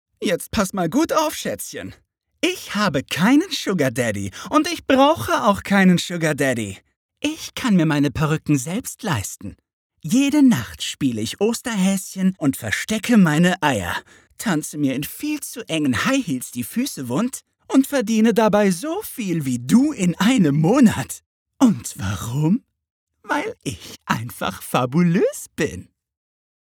Synchron (Demo) „Drag Queen“
queer, selbstbewusst